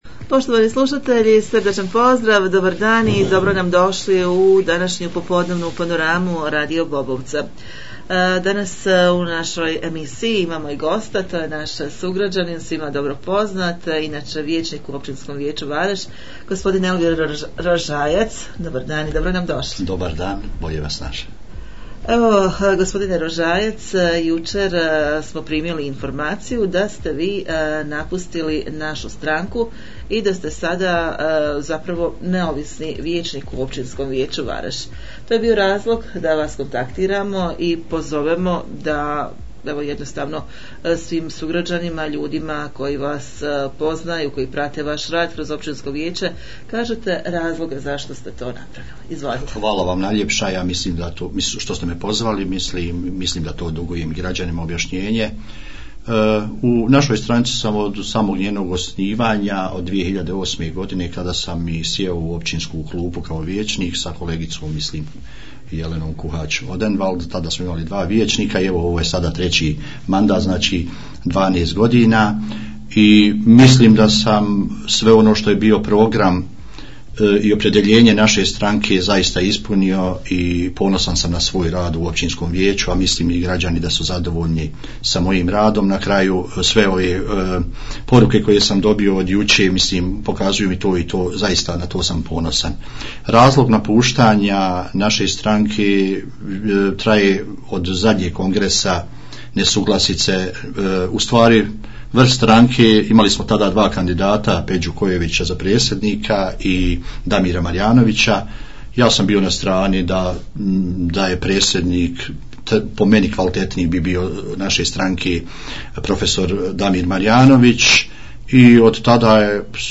U našem studiju (07.07.2020.. godine) ugostili smo vijećnika Elvira Rožajca i razgovarali o napuštanju Naše stranke i budućim planovima... poslušajte